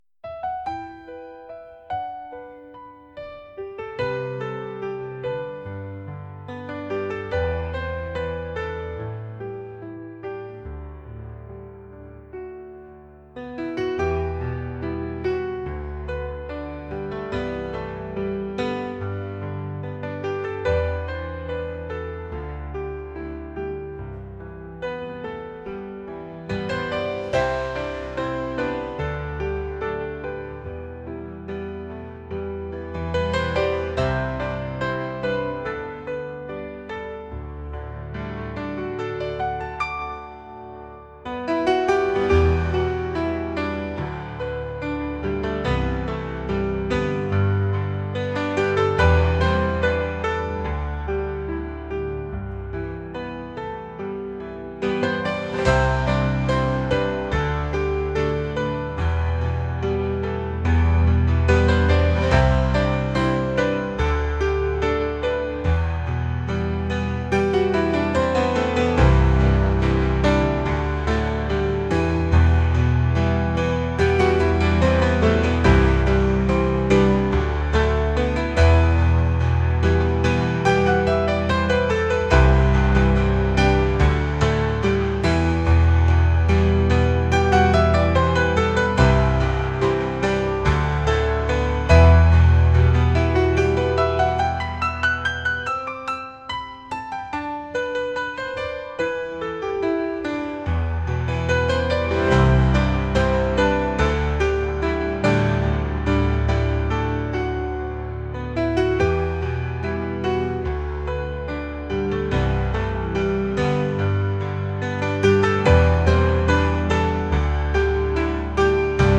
acoustic | pop | indie